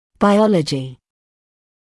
[baɪ’ɔləʤɪ][бай’олэджи]биология